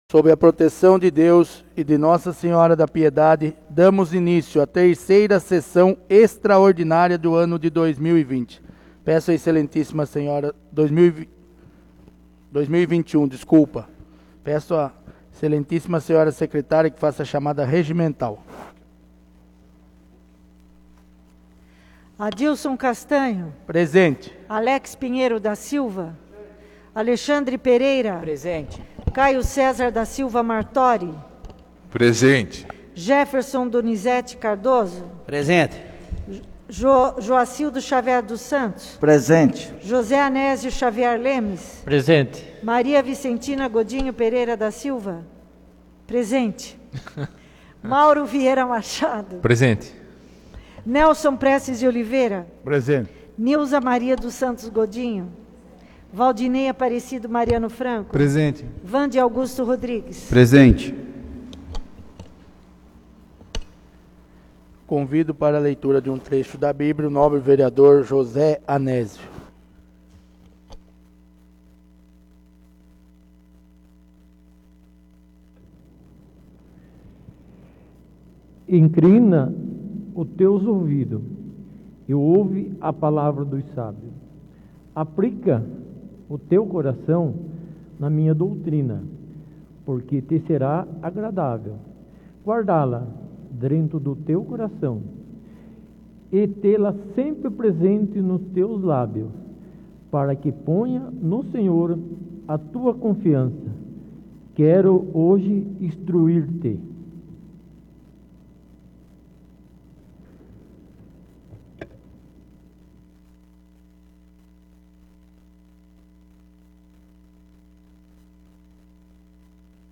3ª Sessão Extraordinária de 2021 — Câmara Municipal de Piedade